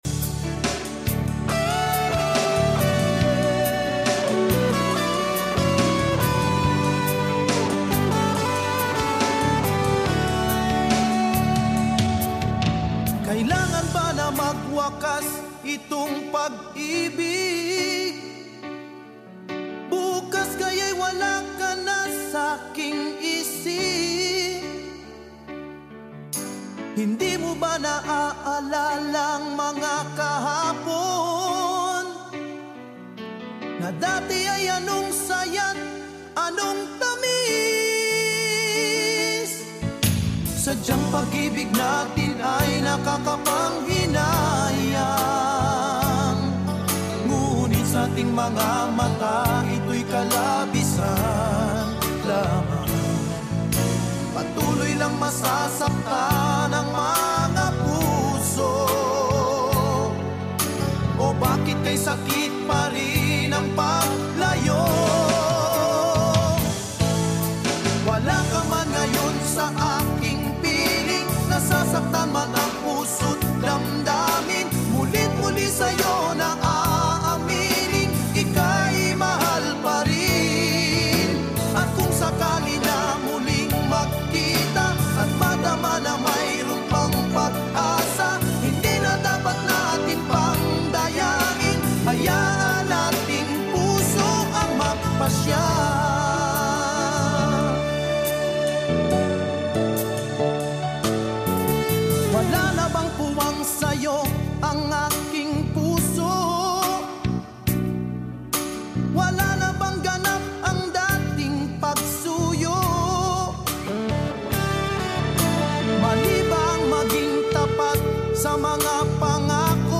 a poignant OPM ballad